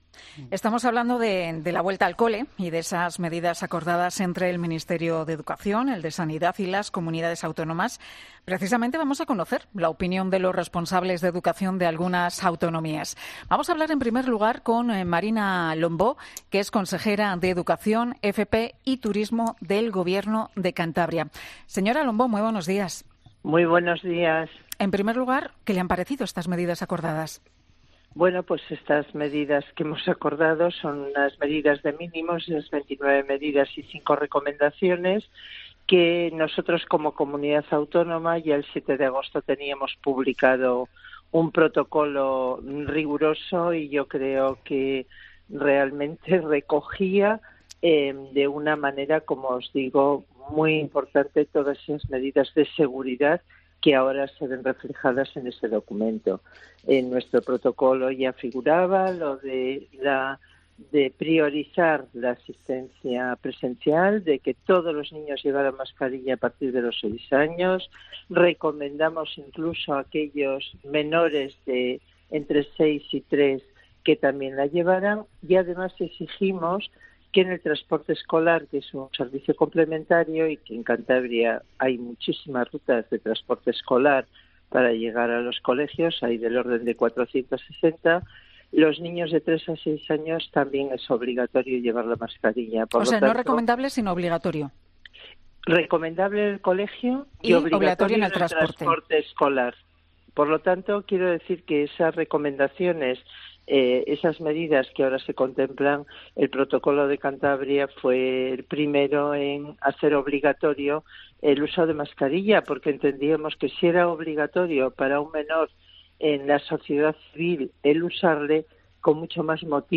Para conocer las reacciones de las comunidades sobre este protocolo, hemos hablado esta mañana en 'Herrera en COPE' con Marina Lombó, la Consejera de Educación del Gobierno de Cantabria.